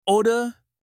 room-in-turkish.mp3